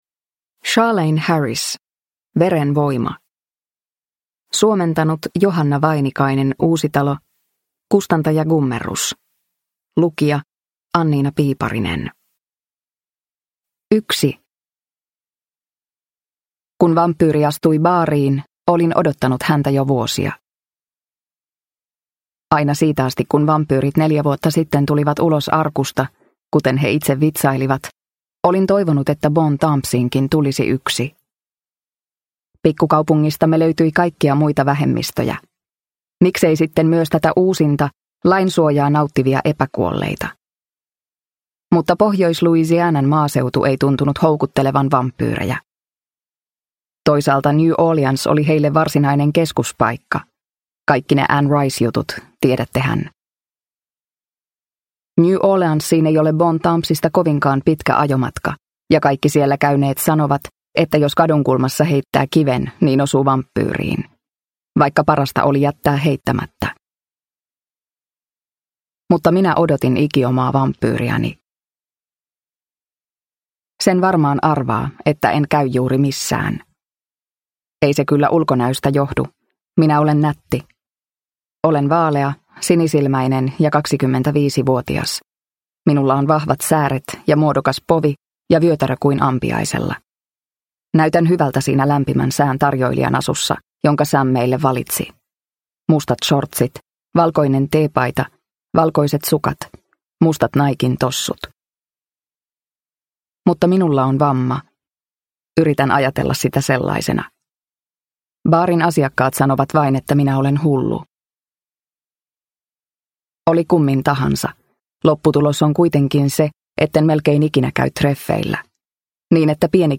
True Blood -menestyssarjasta tuttu Sookie Stackhouse puree myös äänikirjana!